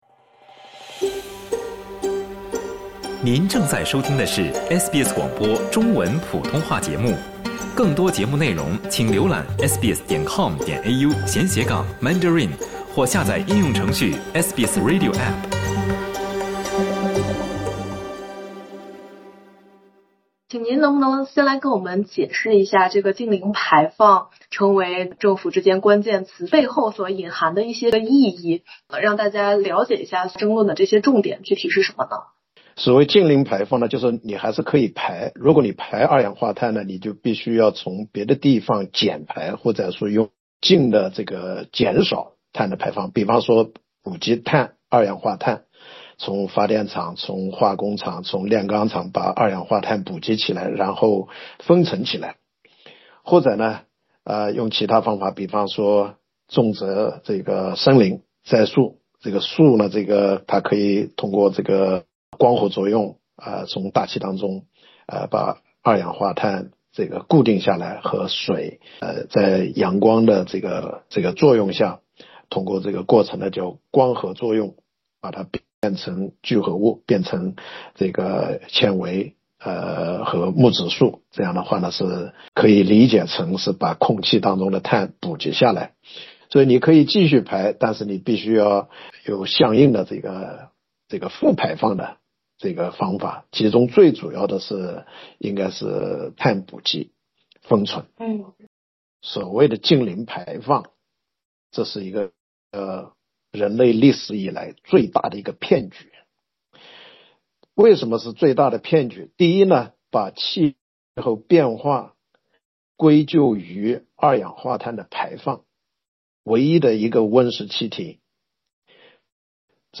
国家党正式宣布放弃2050年“净零排放”目标，而自由党也将在本周明确是否继续坚持这一承诺。这让“净零排放”这个词再次成为澳洲政坛的焦点（点击播客，收听采访）。